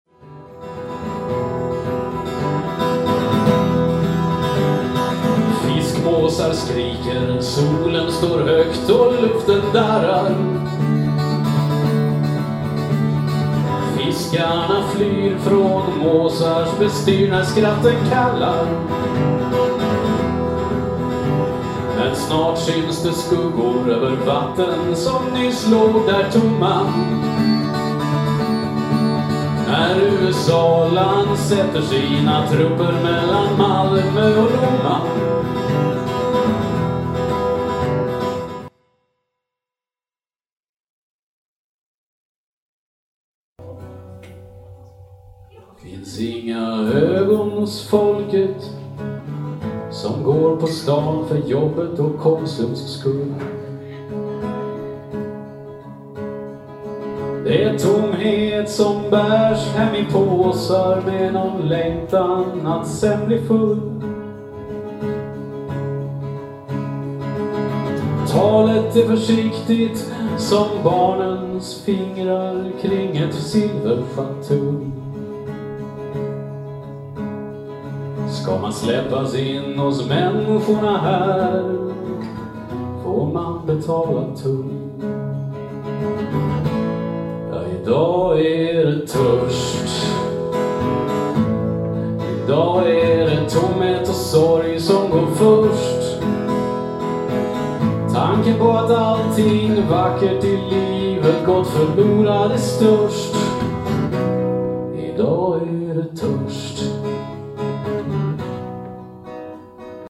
High-end är det dock inte. :) Jag har mer försökt få det att låta som en liveinspelning. Metoden är EQ ungefär enligt förslag ovan och lite reverb och stereoexpander för att försöka dränka "köksklangen", samt lite de-esser för att få bukt med de värsta s-ljuden (tror dock det gjorde mer skada på gitarren än nytta på sången om jag ska vara ärlig).